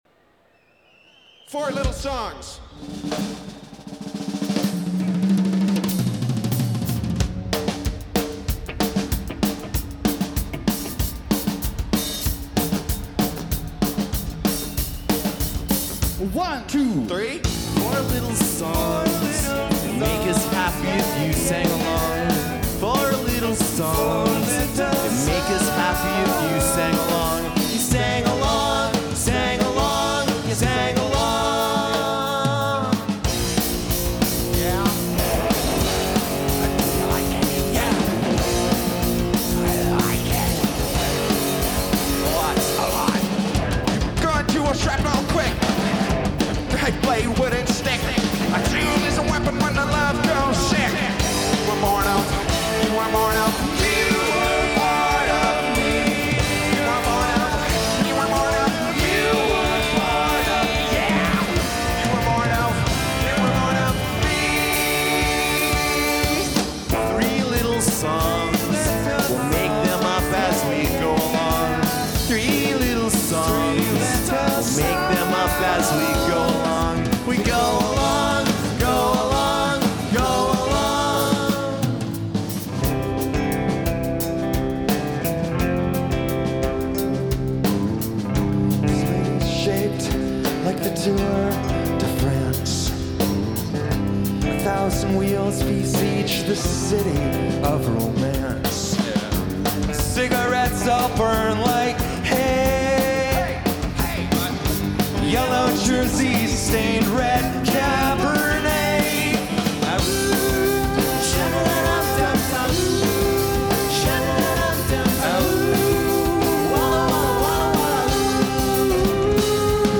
Source 1: DAT -> flac/wav/mp3